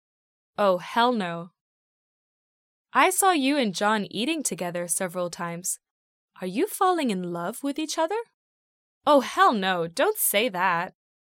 迷你对话